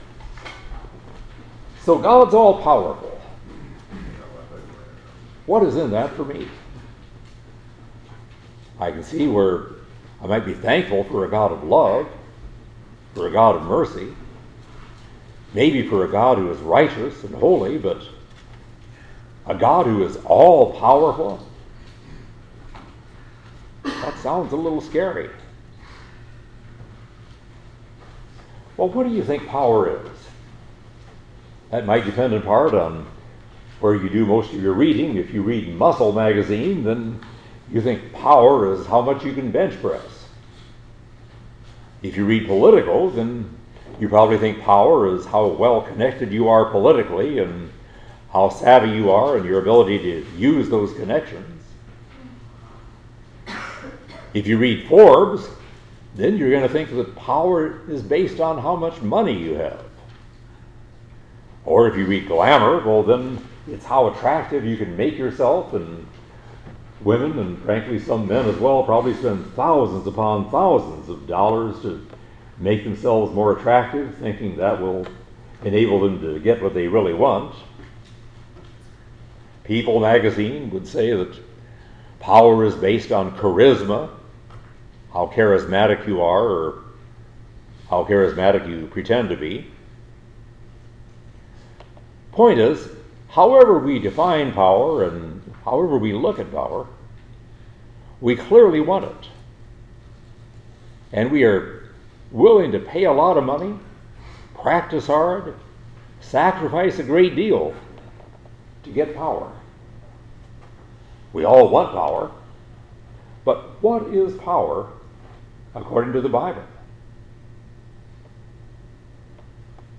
2025 Preacher